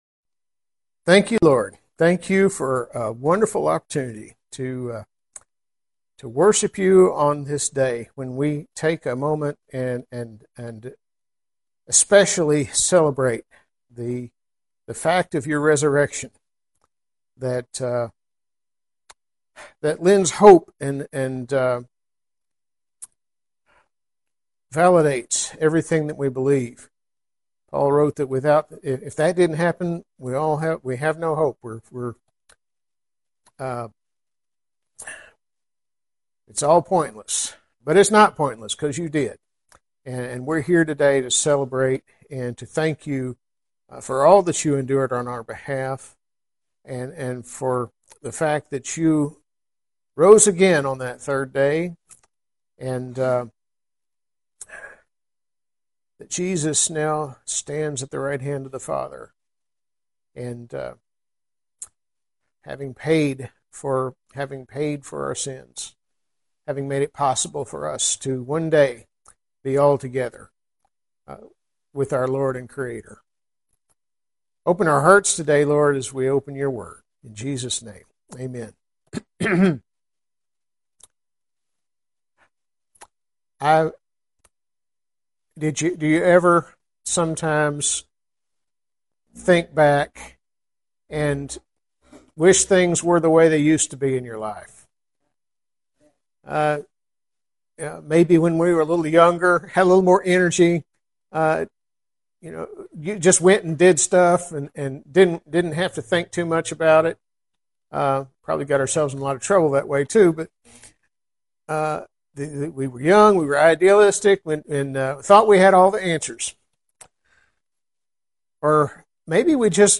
Bartimaeus Baptist Temple Posted on April 20